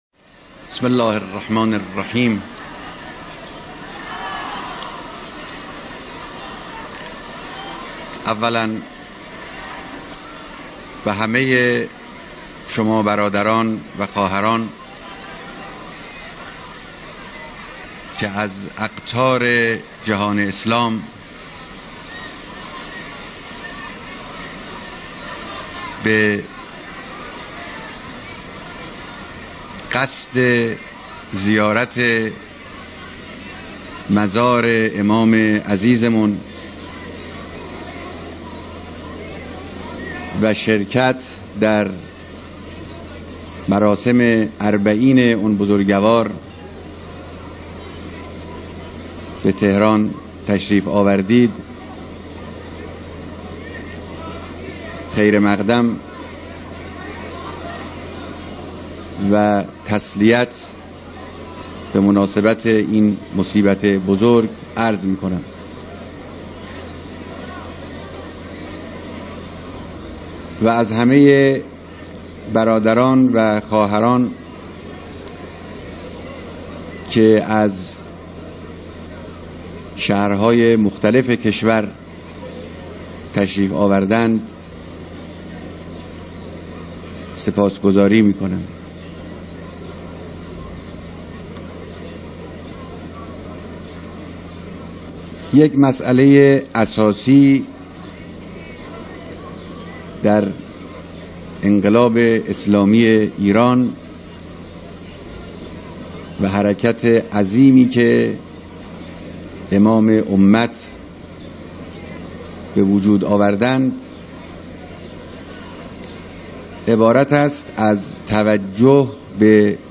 بیانات رهبر انقلاب در دیدار میهمانان خارجی مراسم اربعین امام خمینی(ره)